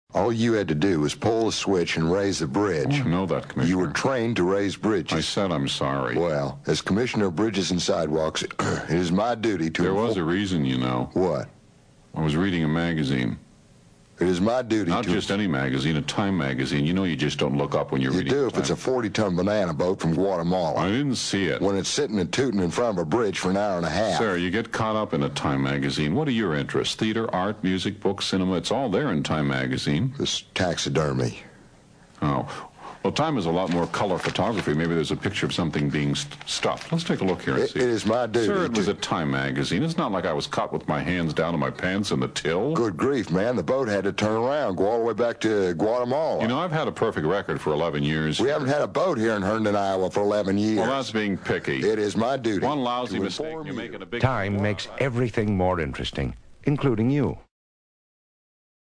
The late Dick Orkin was a master at creating great theater-of-the-mind using dialogue, as in this spot for Time Magazine: